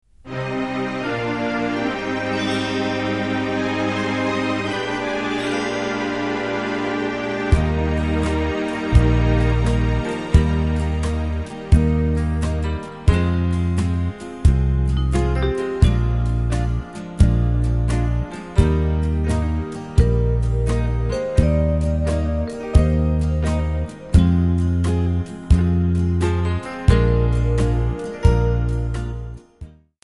Backing track Karaoke
Oldies, Country, 1960s